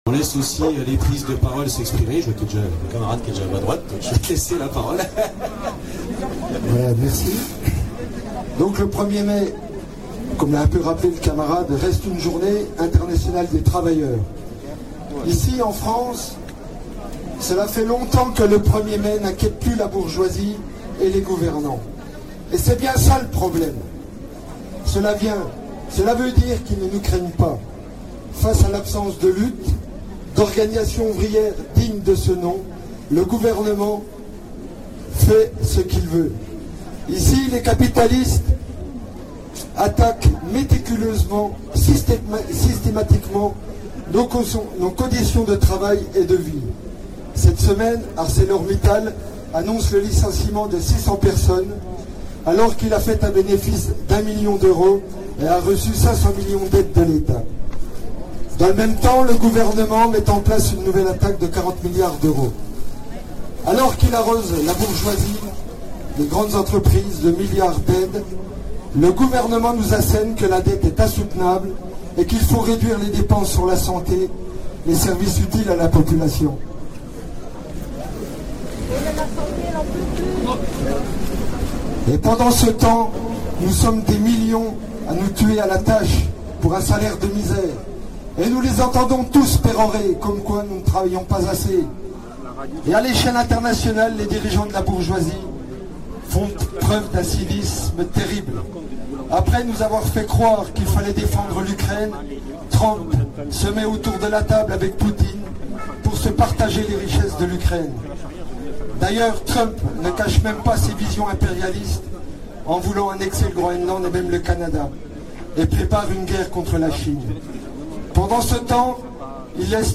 Manifestation du 1er Mai 2025 à Belfort